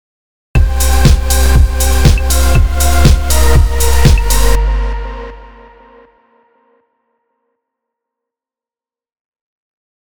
So for my own study, I focused on sidechaining the pad to the kick. I also experimented with adding motion on the pad itself, either through an LFO or through glue compression, depending on what gave me the right feel.
ghosts-sidechain.mp3